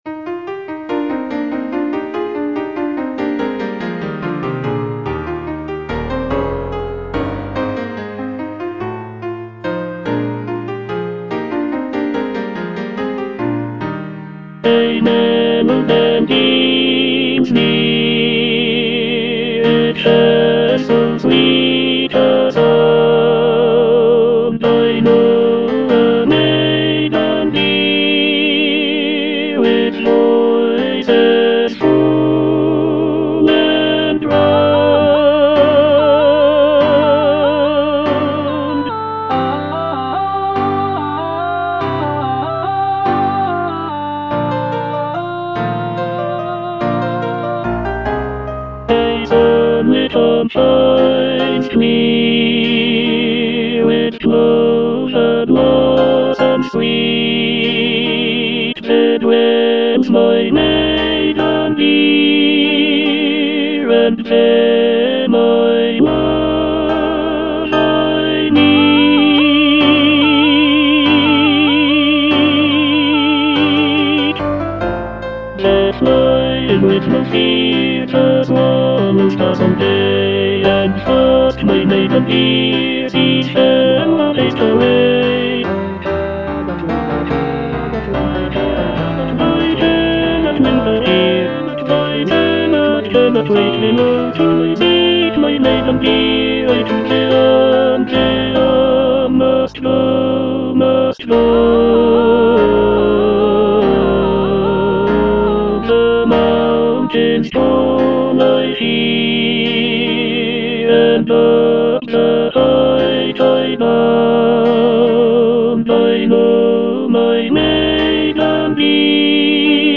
E. ELGAR - FROM THE BAVARIAN HIGHLANDS On the alm (tenor II) (Emphasised voice and other voices) Ads stop: auto-stop